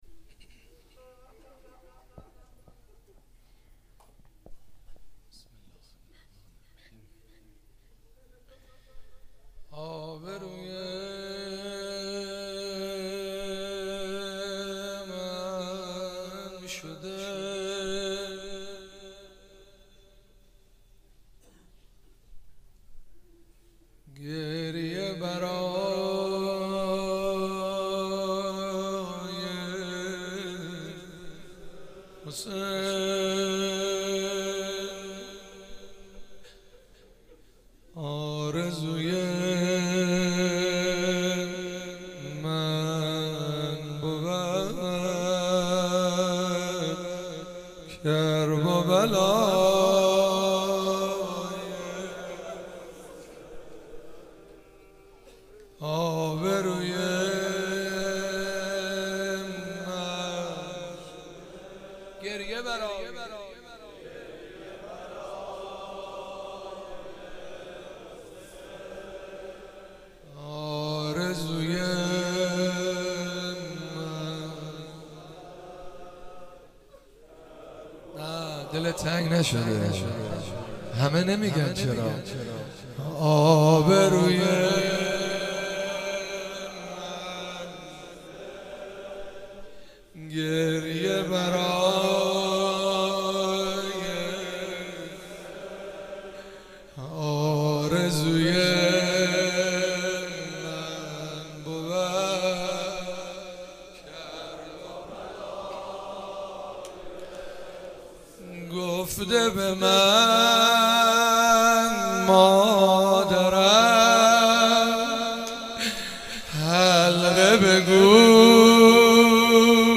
مراسم شب چهارم محرم الحرام 96
حسینیه حضرت زینب (سلام الله علیها)
روضه